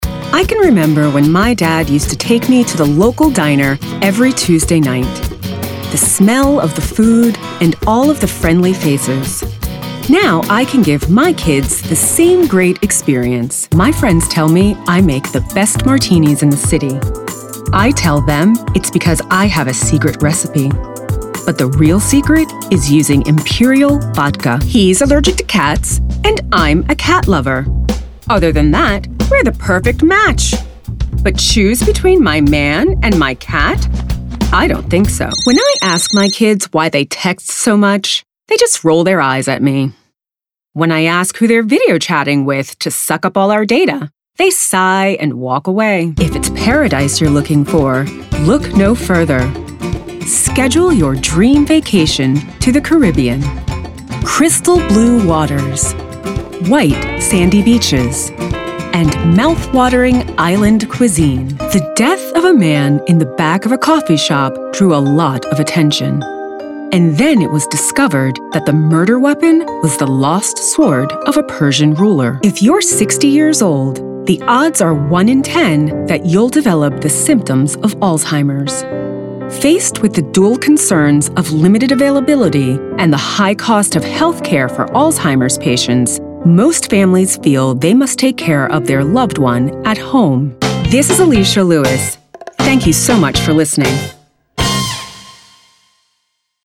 Precise, articulate delivery.
Commercial Reel (96s)
- Calm, credible, and grounded vocal presence
- Treated home booth